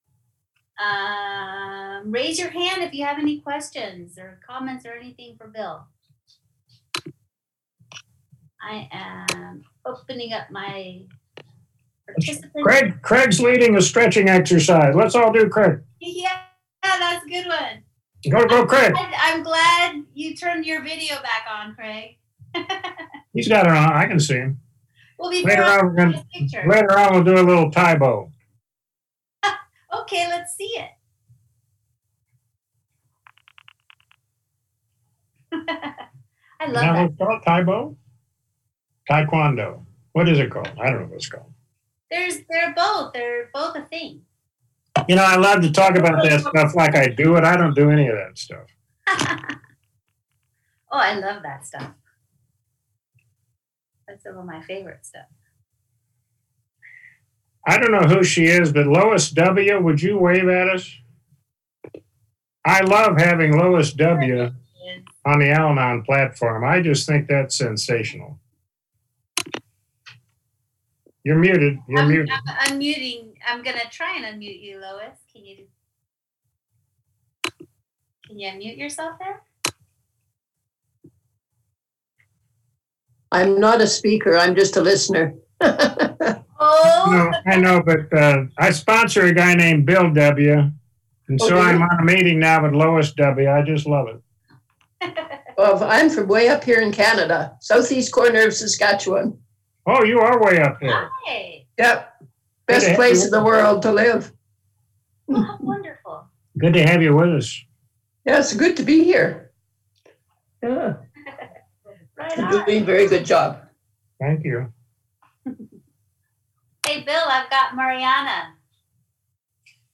Emotionally Sobriety - AWB Workshop